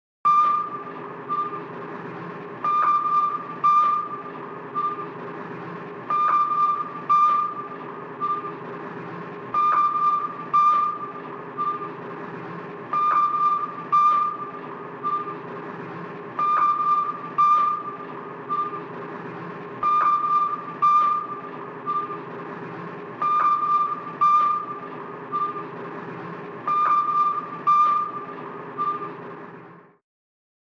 Звуки сонара подводной лодки
На этой странице собраны звуки сонаров подводных лодок — от монотонных импульсов до сложных эхолокационных сигналов.